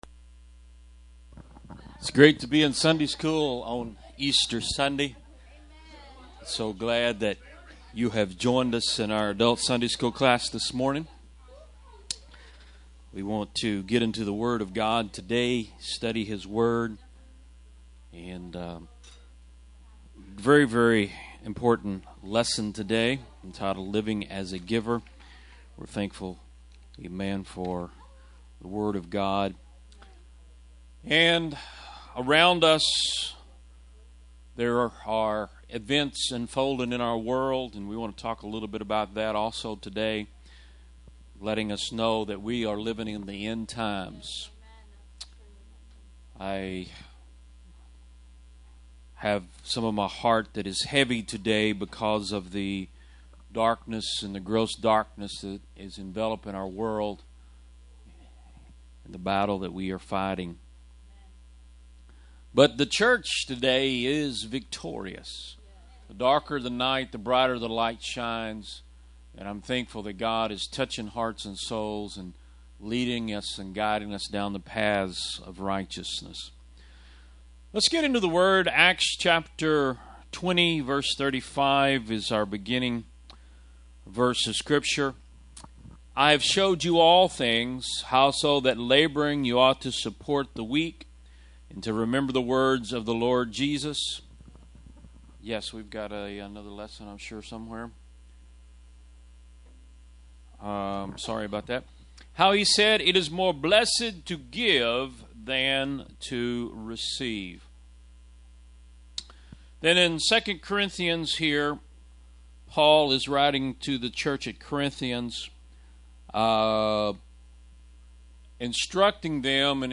Adult Sunday School 4-5-15